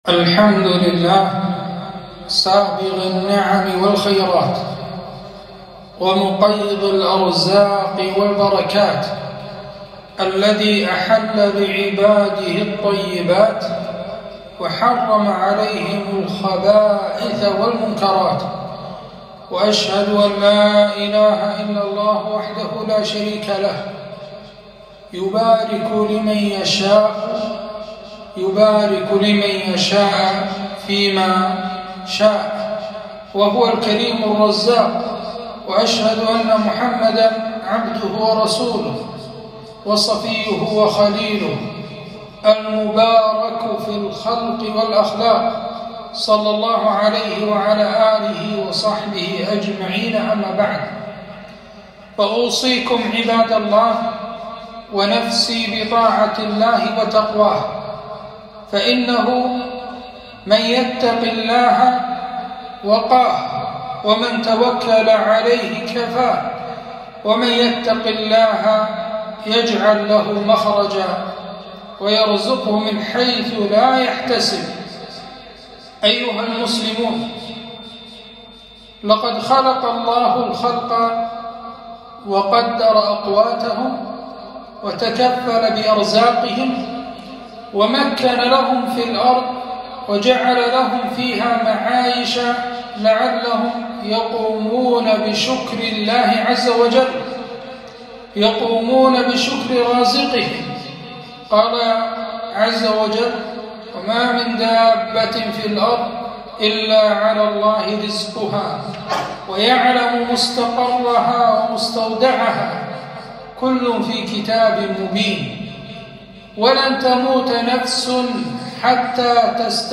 خطبة - البركة في حياة المسلم